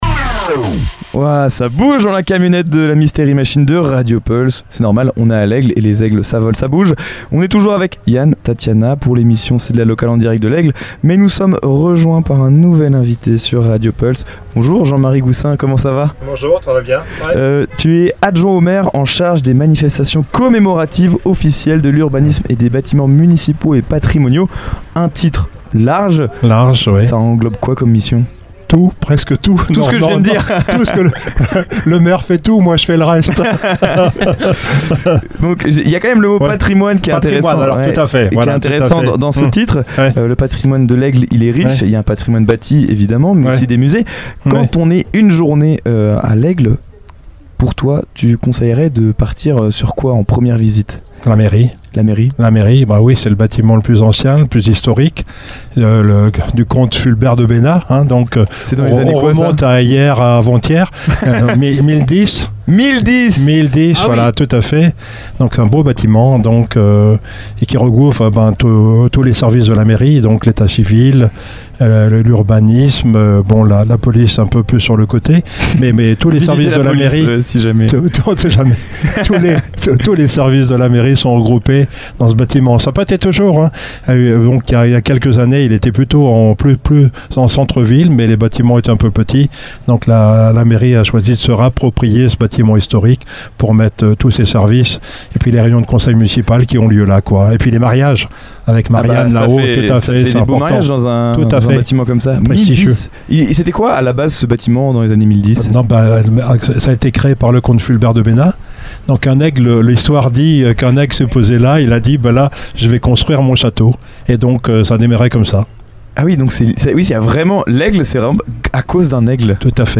Une interview riche et pragmatique qui permet de mieux comprendre le fonctionnement et les ambitions d’une ville en mouvement.